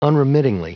Vous êtes ici : Cours d'anglais > Outils | Audio/Vidéo > Lire un mot à haute voix > Lire le mot unremittingly
Prononciation du mot : unremittingly